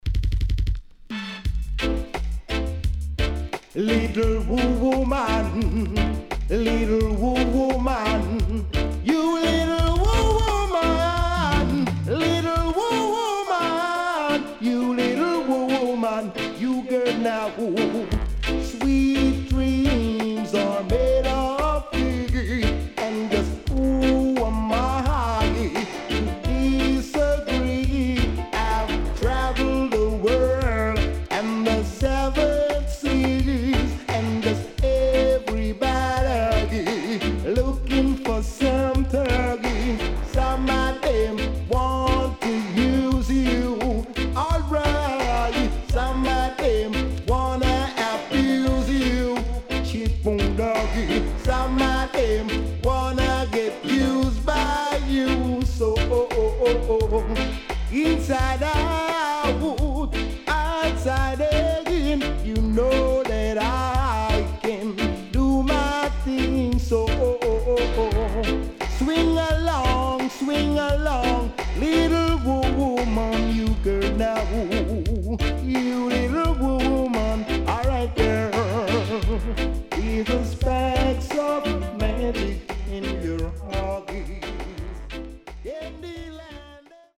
少しチリノイズ入りますが良好です。